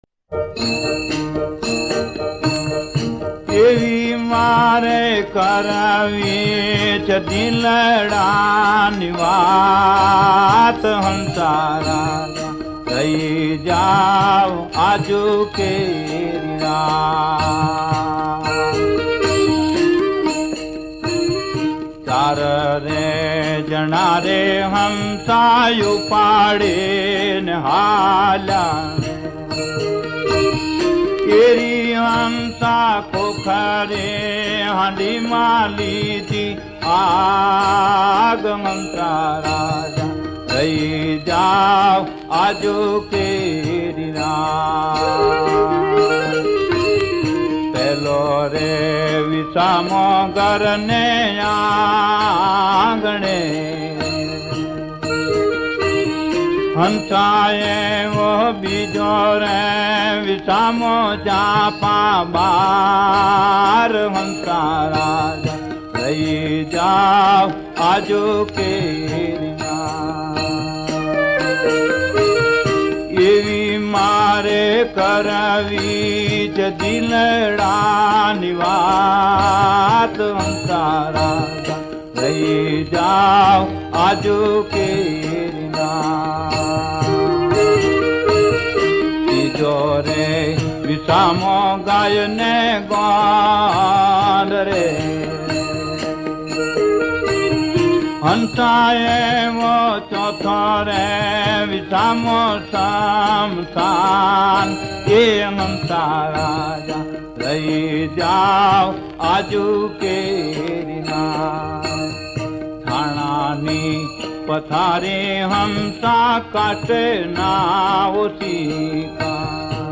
ગીત સંગીત ભજનાવલી - Bhajans